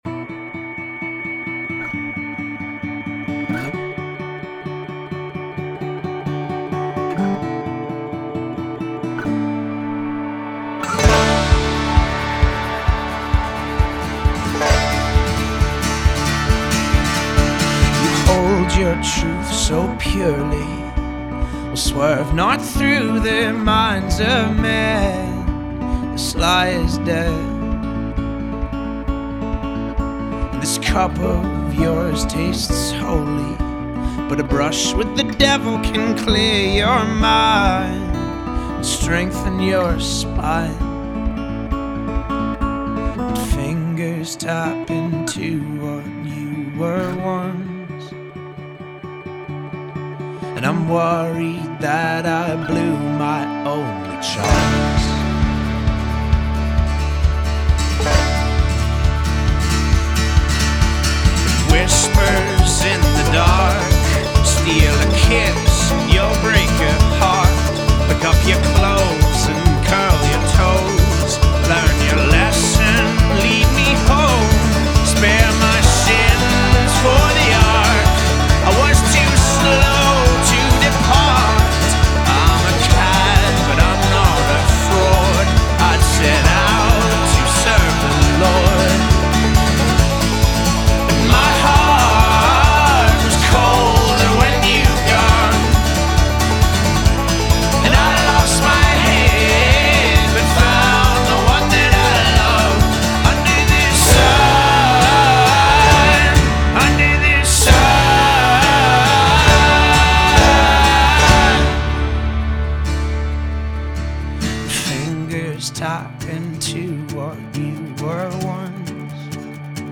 Style: Folk Rock